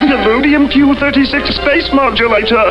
Here's the sound I have on my new, new, new gp2x:
I had to edit out the word "explosive" from "Illudeum PU-36 Explosive Space Modulator," partly because the sound clip was too long with it, and partly because I don't want something explosive in my hands when I'm playing Metal Slug.